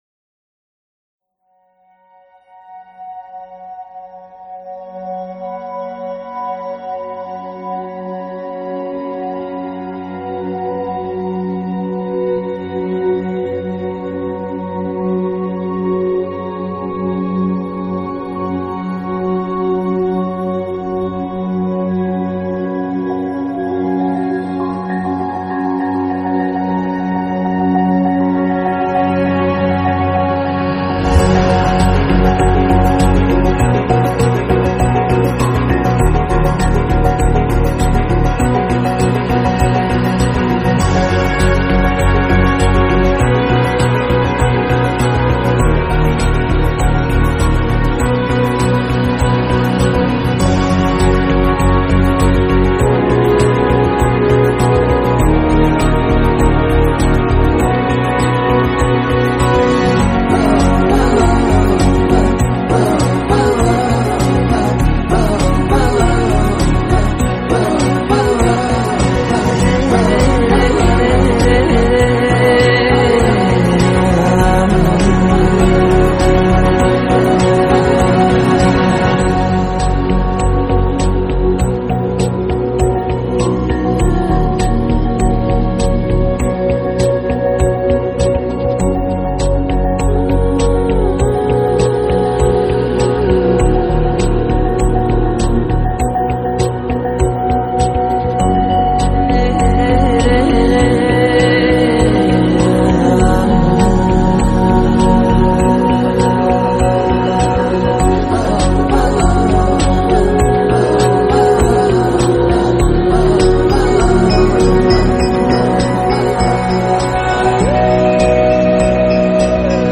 Soundtrack, Modern Classical, Electronic